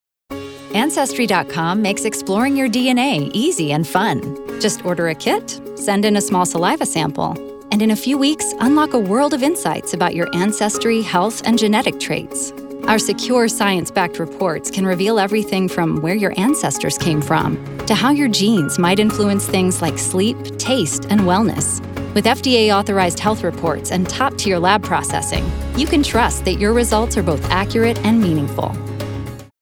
Englisch (US)
Native Voice-Samples
Erzählung